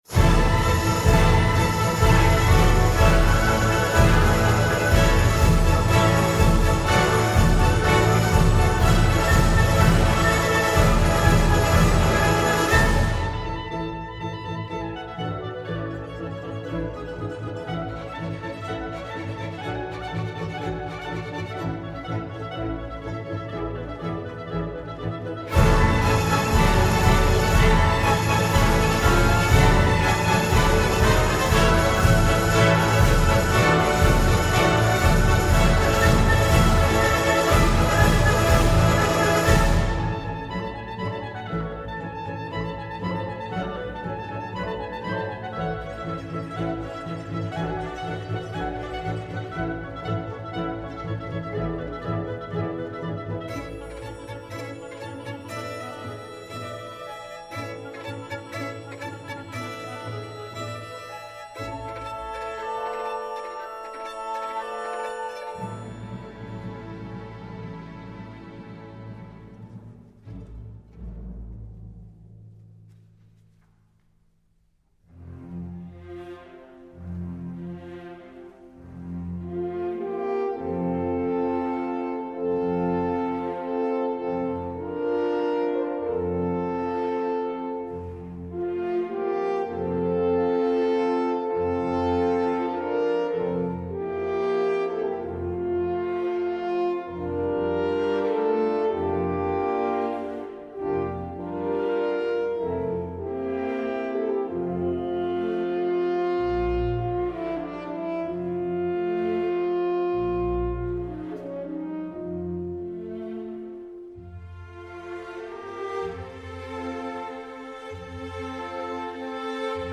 ★ 由一流英國樂團、指揮、女高音齊力演出，展現古典音樂中節奏強烈的西班牙迷人風情！
★ 直刻錄音，錄製過程一氣呵成，音質遠勝一般CD！
★ 以 Neumann U47 Decca 樹收音，錄音如臨現場！